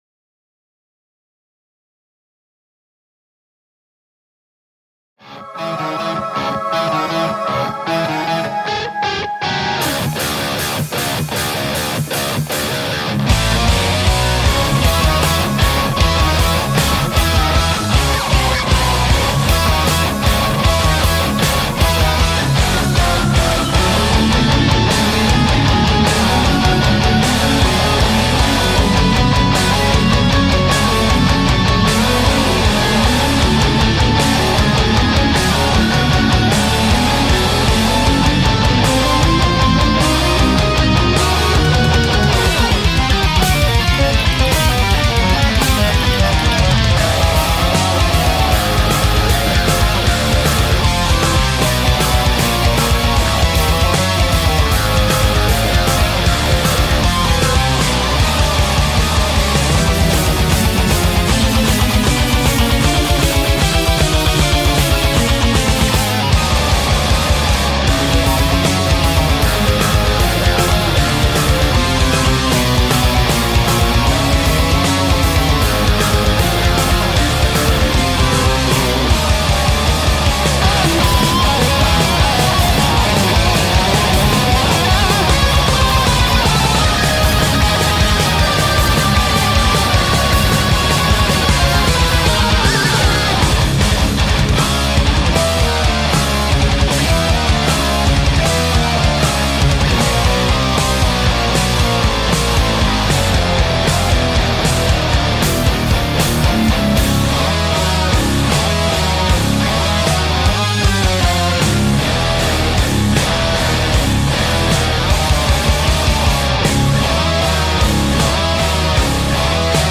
Mic: - Shure SM57 (Guitar)